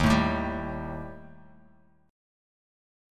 D#Mb5 chord